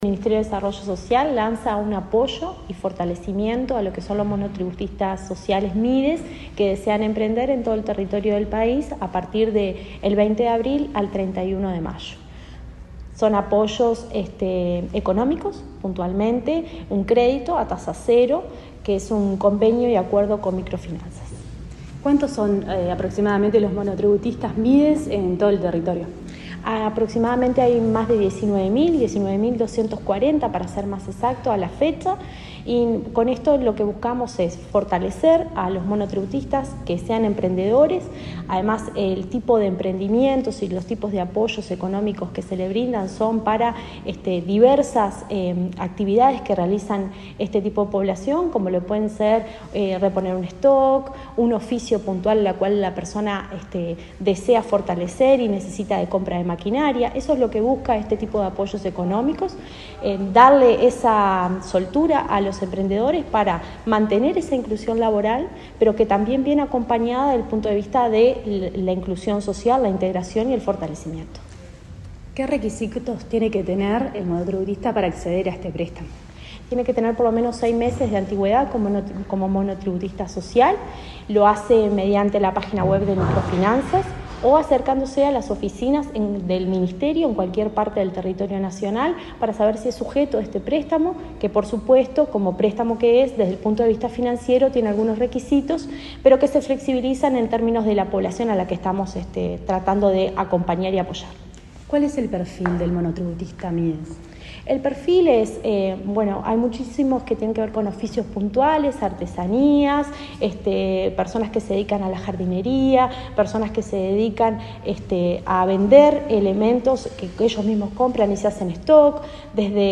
Entrevista a la directora nacional de Desarrollo Social, Cecilia Sena